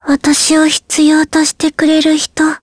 Jane-vox-select_jp.wav